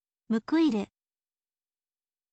muku iru